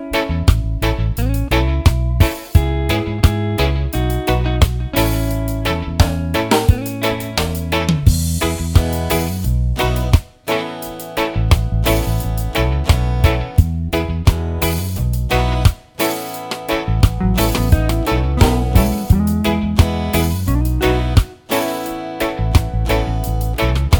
no Backing Vocals Reggae 2:55 Buy £1.50